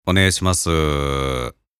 宅配・配達・デリバリーサービスの受取時に使える！「低い声の男性が一緒にいる」と思わせるための無料ボイス集◎
（恐い感じを出すと逆に面倒が起こる可能性があるので、普通のトーンにしています。）